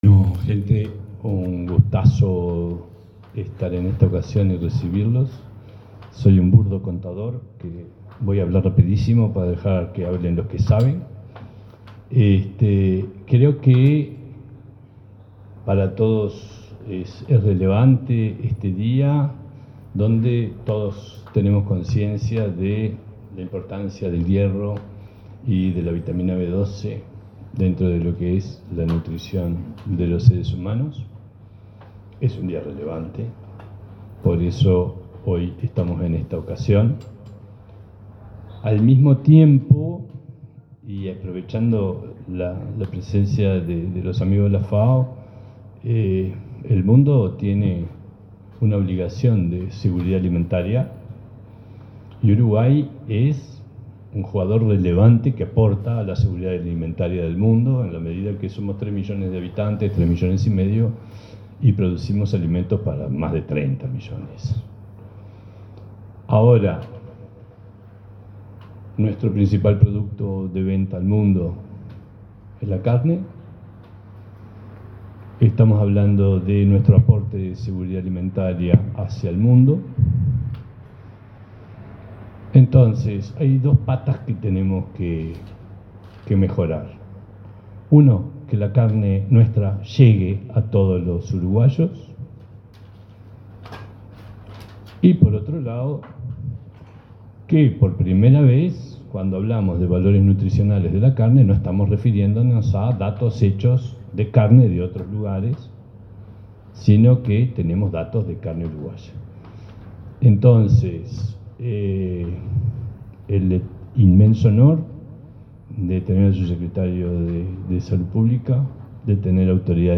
Palabras del presidente del INAC, Gastón Scayola